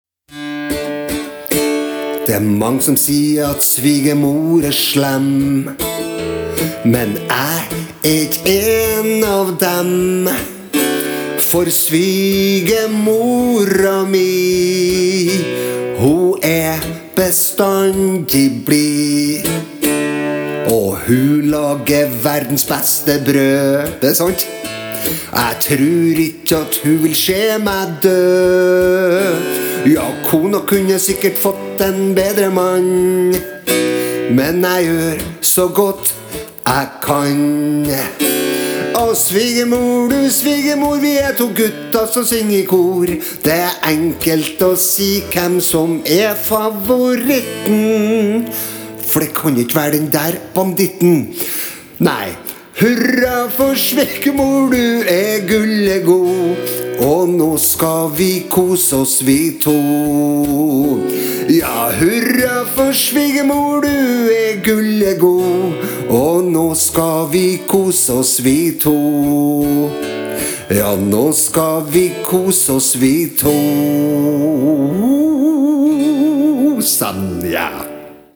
Jeg laget den som en vals, så kanskje jeg får meg en svingom også.